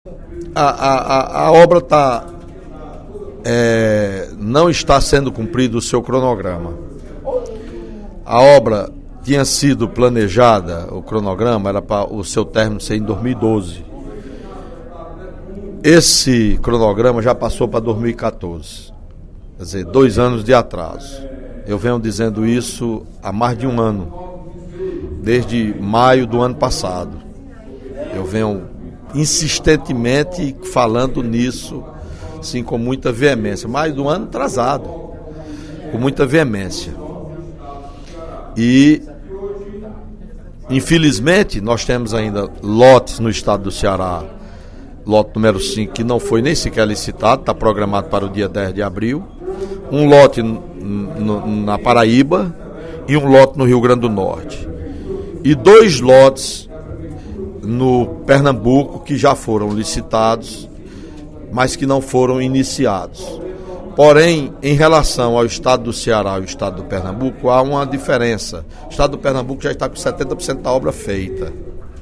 O deputado Welington Landim (PSB) criticou, durante pronunciamento na sessão plenária desta quinta-feira (29/03), o cronograma das obras da transposição do São Francisco. Segundo ele, o projeto não anda na velocidade programada e é constantemente paralisado.